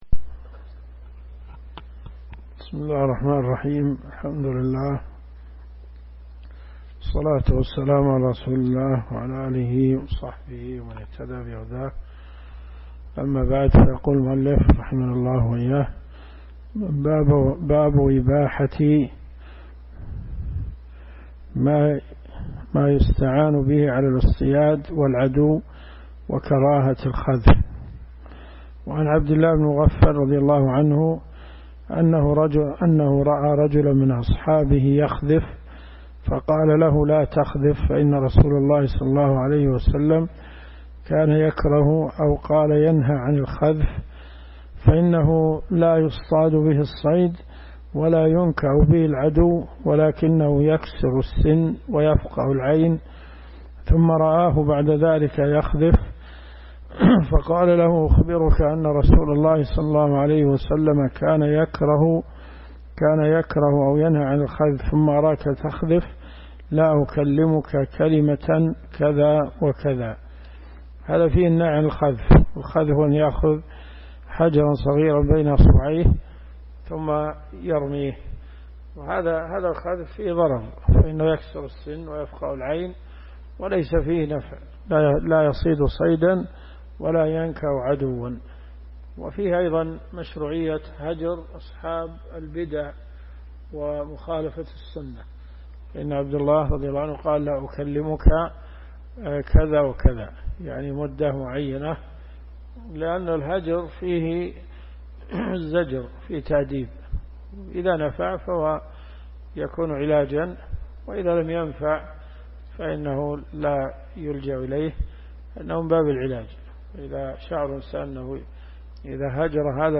الكتب المسموعة